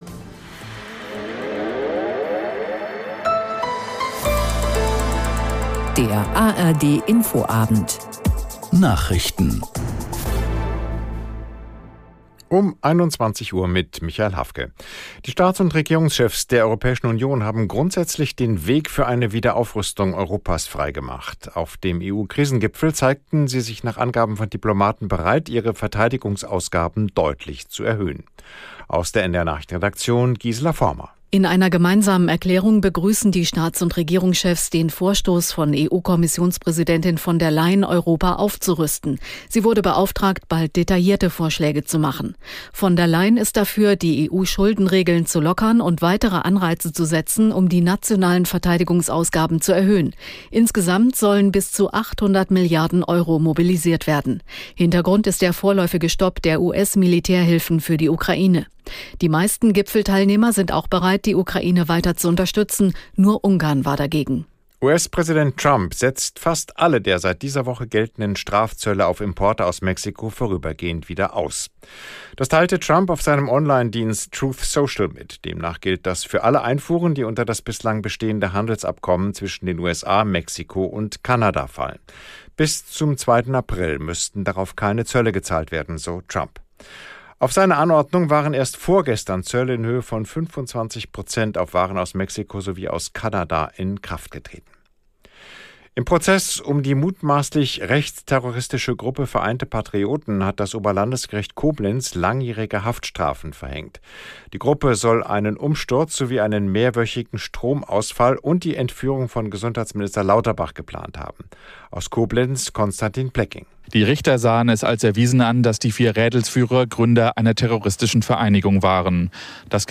Tägliche Nachrichten Nachrichten NDR Info